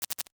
NOTIFICATION_Subtle_06_mono.wav